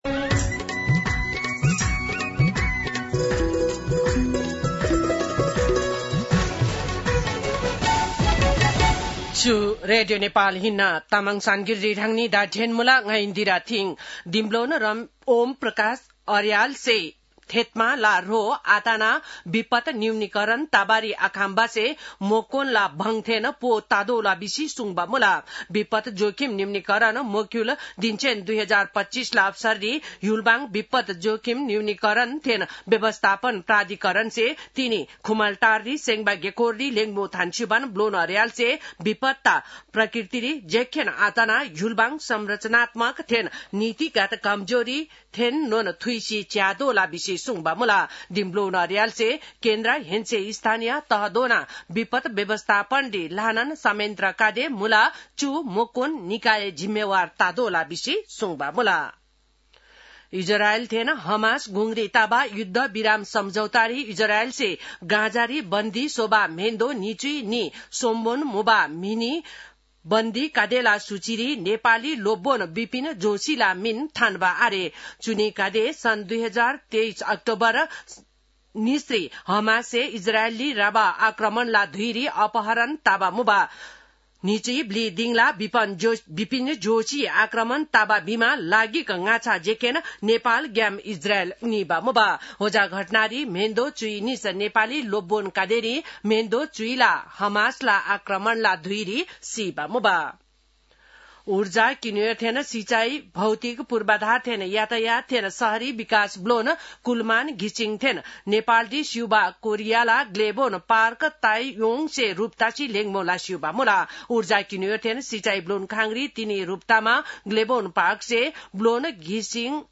तामाङ भाषाको समाचार : २७ असोज , २०८२